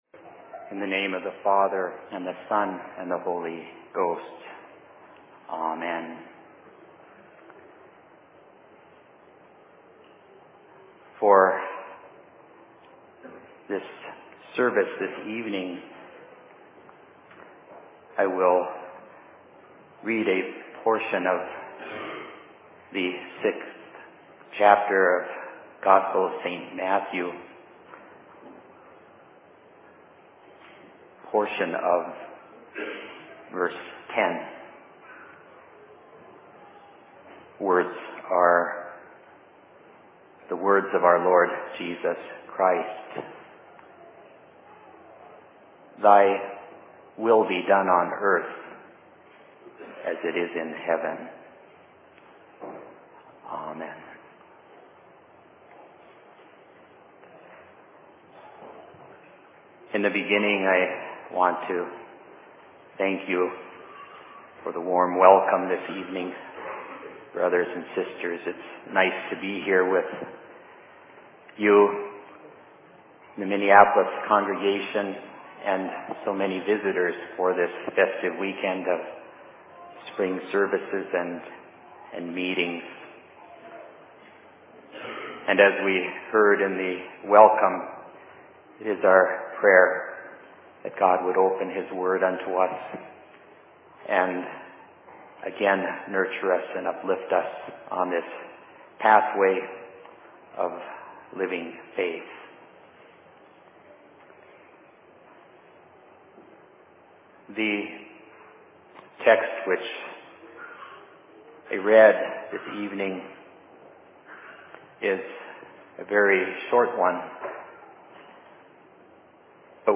Sermon in Minneapolis 24.04.2009
Location: LLC Minneapolis